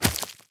fst_spidergiant_001.wav